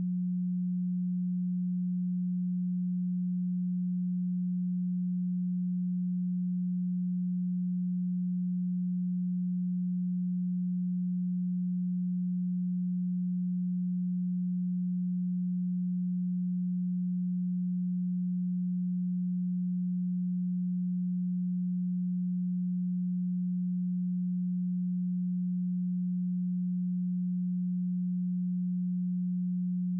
180Hz_-28.dB.wav